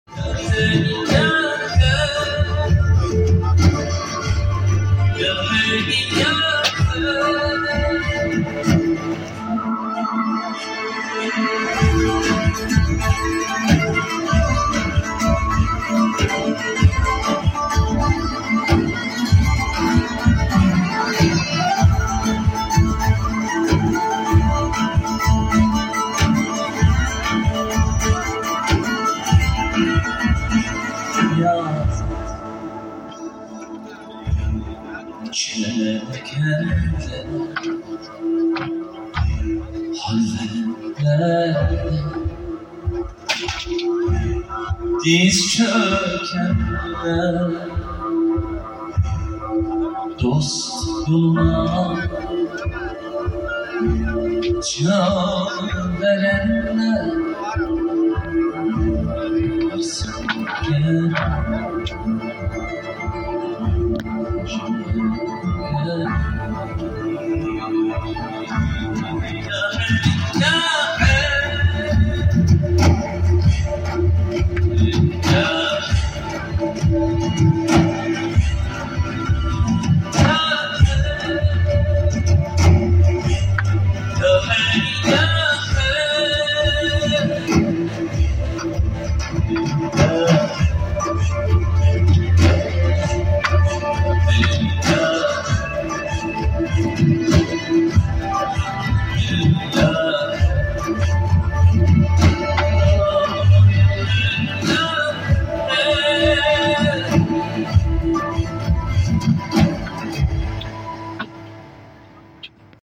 bu akşam başlıyor provalardan biraz görüntüler